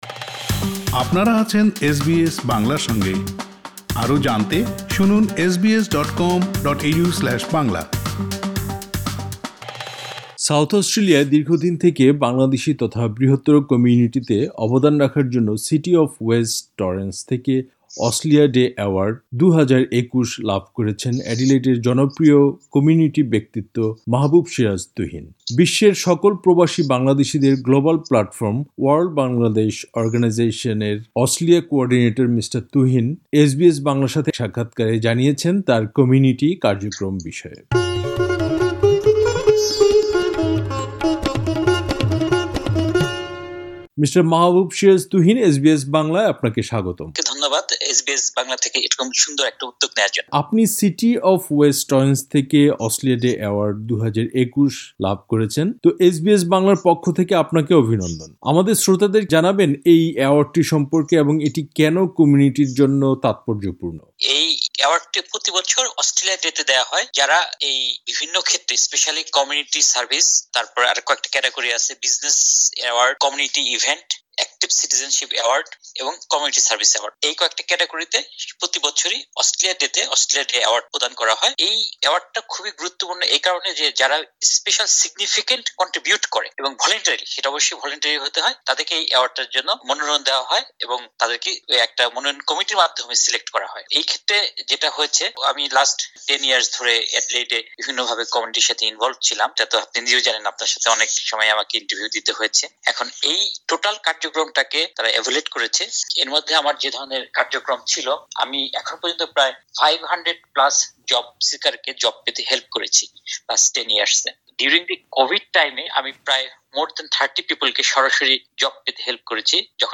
এসবিএস বাংলার সাথে এক সাক্ষাৎকারে তিনি জানিয়েছেন তার কমিউনিটি কার্যক্রম বিষয়ে।